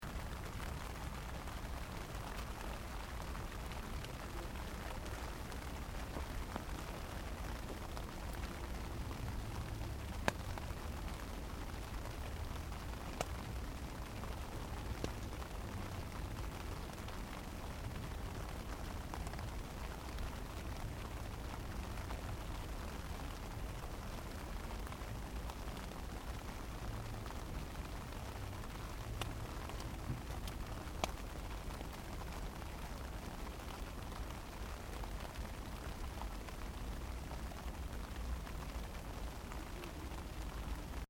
Pioggia.mp3